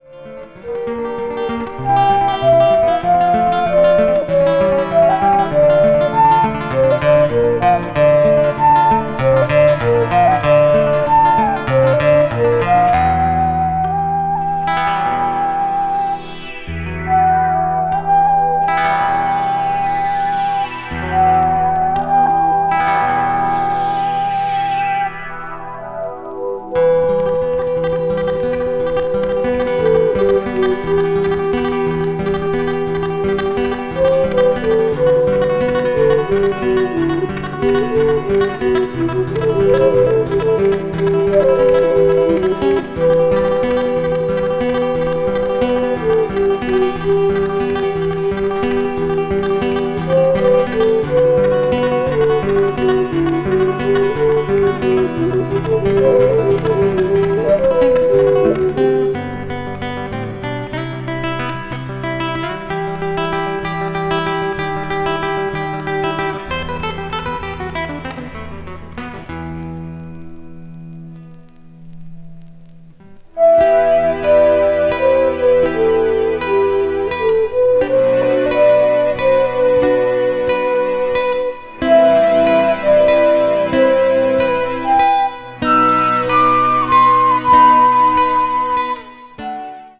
一瞬いなたく聞こえるかもしれませんが、聴いているうちにその世界に引き込まれていってしまう不思議な音楽です。